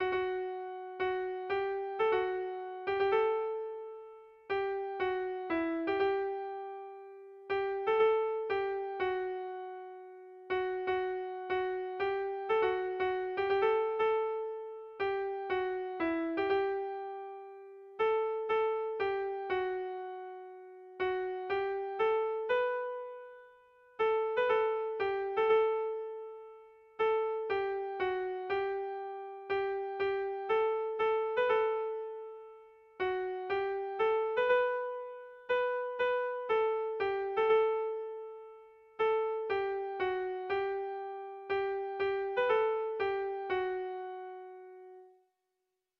Sentimenduzkoa
AABB2